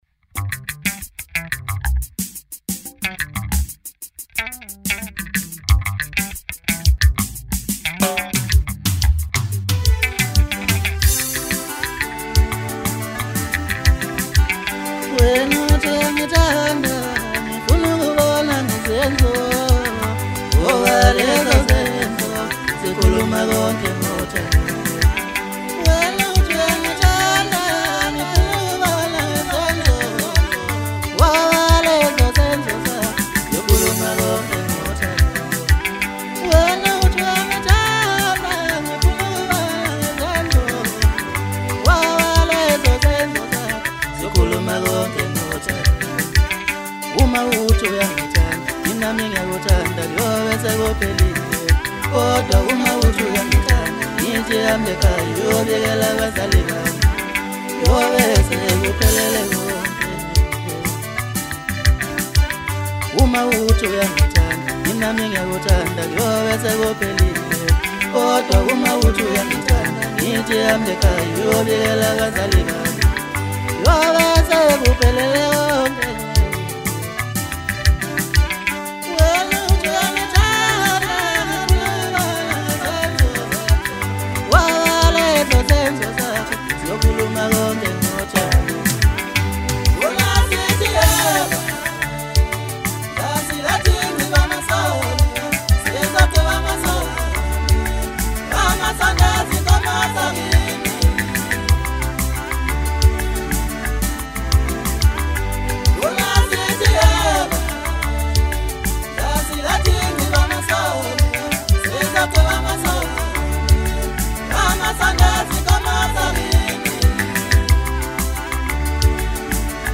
Home » Maskandi » Biography